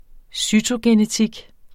Udtale [ ˈsytogenəˌtig ]